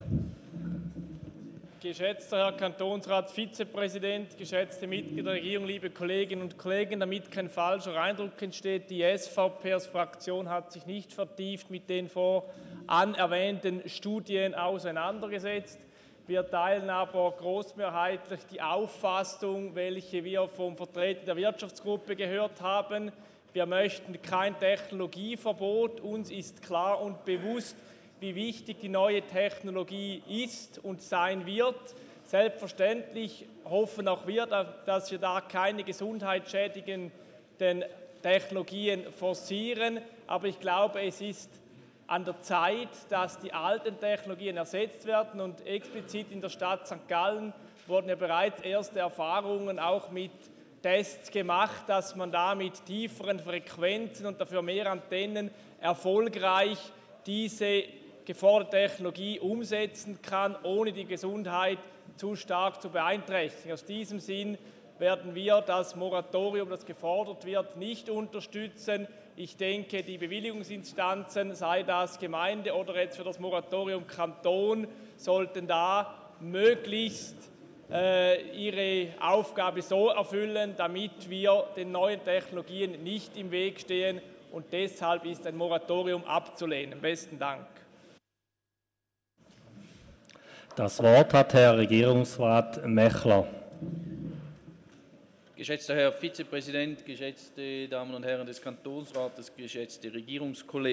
12.6.2019Wortmeldung
Session des Kantonsrates vom 11. bis 13. Juni 2019